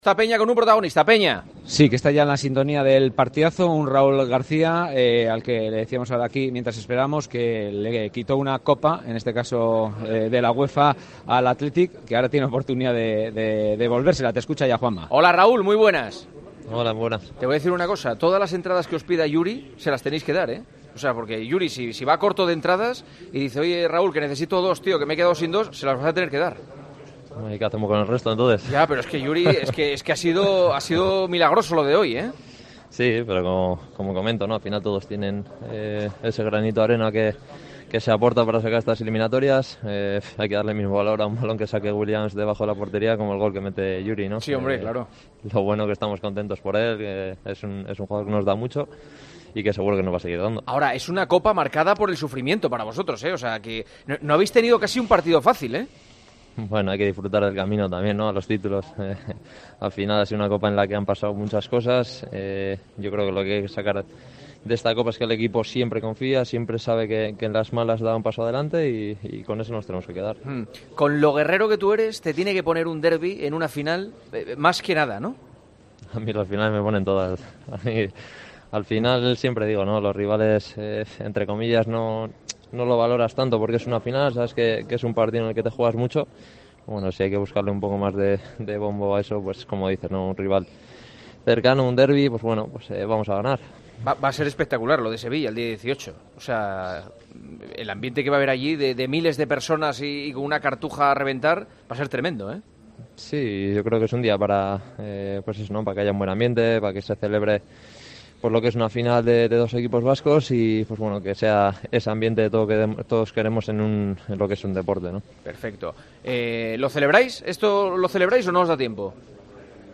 AUDIO: El jugador del Athletic repasó el camino del conjunto vasco hacia la final de la Copa del Rey, en El Partidazo de COPE.